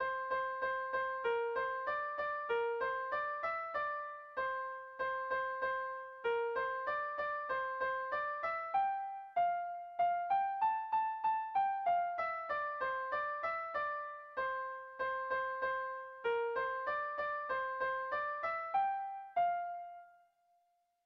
Air de bertsos - Voir fiche   Pour savoir plus sur cette section
Kontakizunezkoa
Ezkurra < Baztan Bidasoa < Nafarroa < Euskal Herria
Zortziko txikia (hg) / Lau puntuko txikia (ip)
A1A2BA2